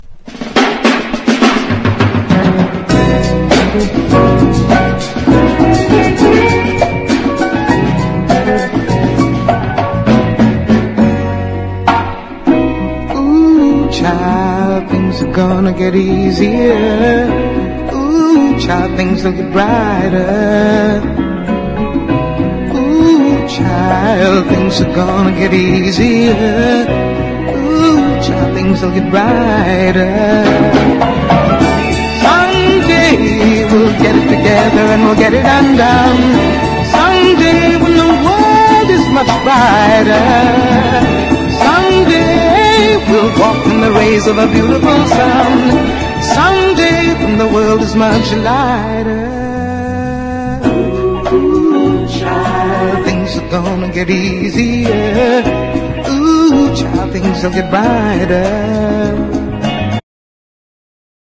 JAPANESE LOUNGE / LOUNGE FUNK
和モノ・ラウンジ・グルーヴ！
揺らめくギター＋鍵盤が妖艶な空気を醸し出す